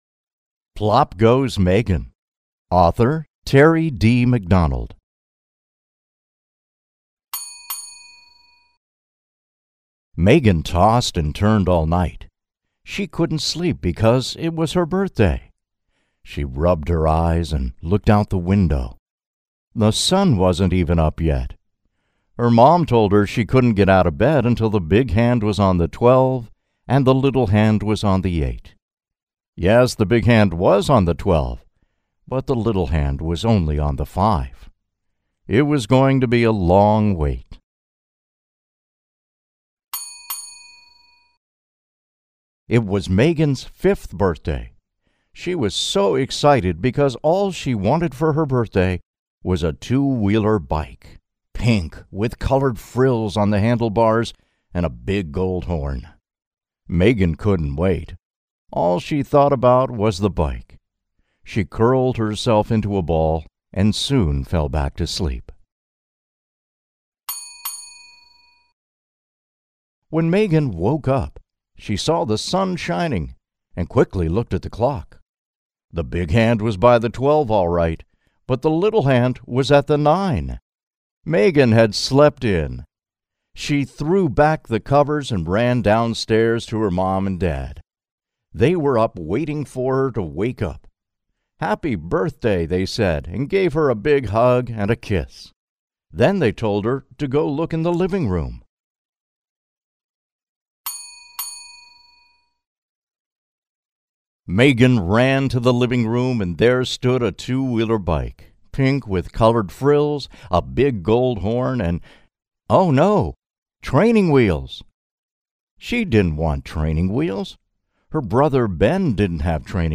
PLOP GOES MEGAN AUDIO BOOK
Audio includes the ring of a bicycle bell to indicate the next page